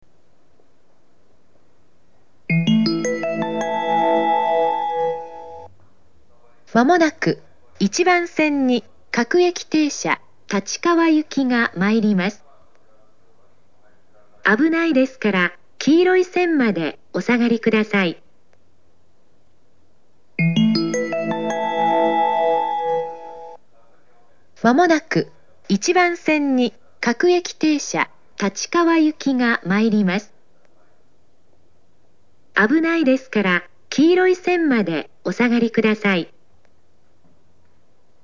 旧駅名標及び旧スピーカー（現在は撤去されております）
１番線接近放送
接近放送は「各駅停車　立川行」です。
minami-tama1bansen-sekkin2.mp3